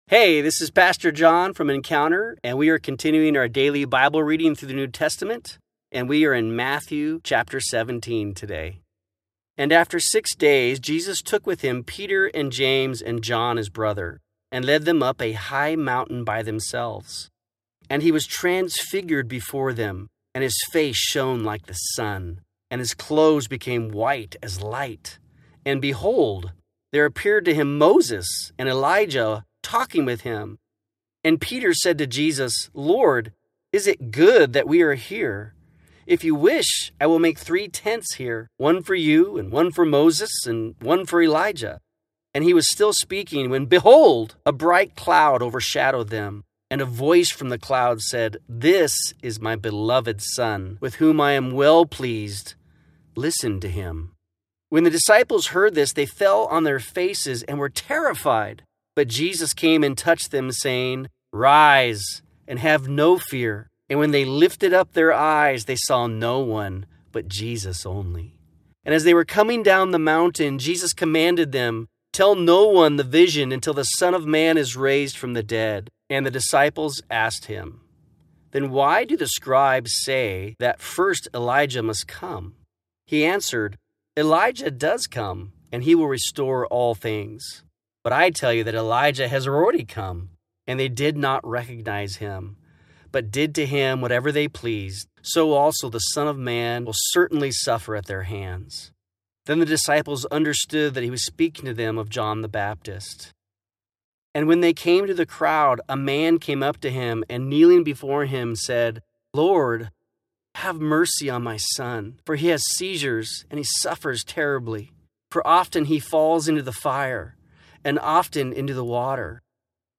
New Testament Bible Reading Plan – Audio Version